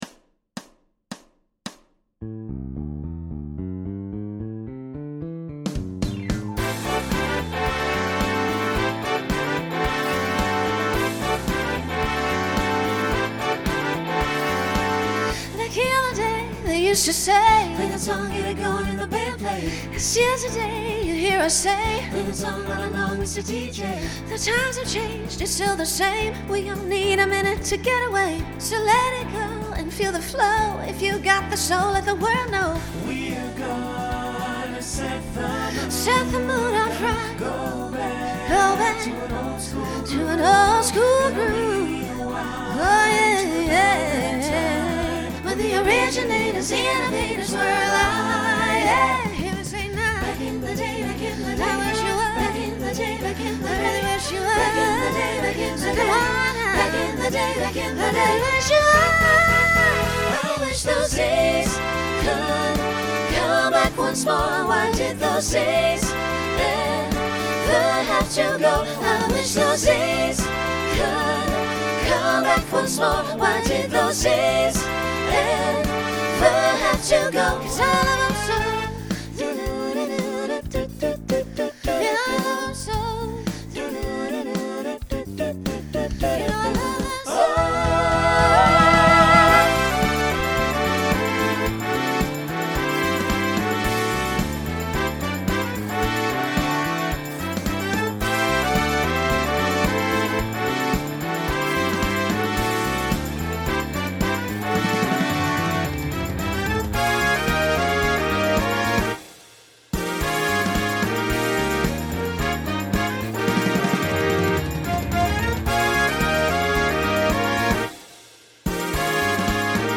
Voicing SATB